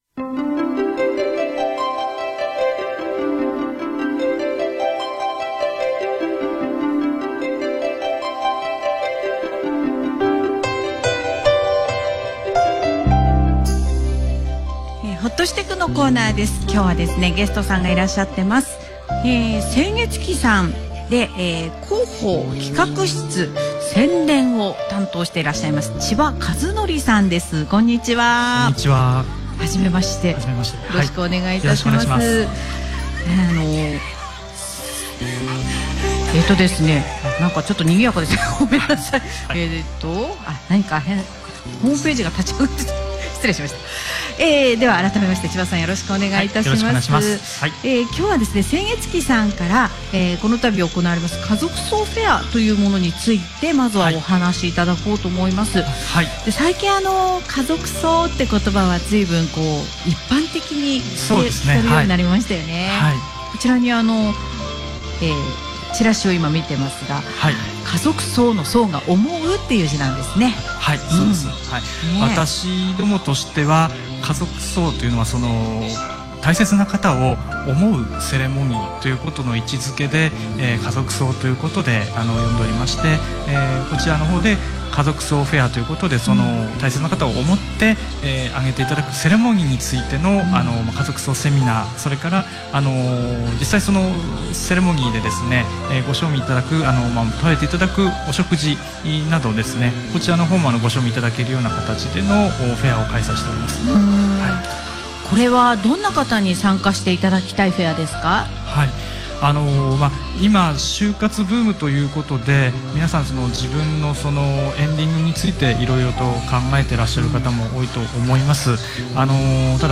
本日、「エフエムたいはく」の人気コーナー ”ほっとすて～しょん” に生出演させていただきました。